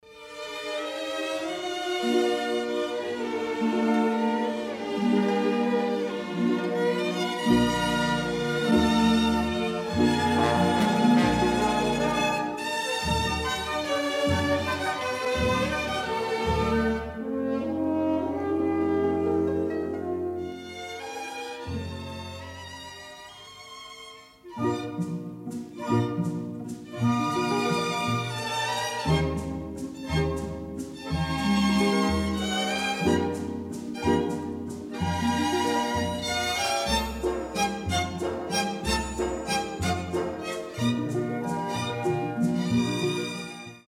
Die Restauration (nach Überspielung) hat keine Klangeinschränkungen bewirkt.
klassik_gewaschen_basisrestauration.mp3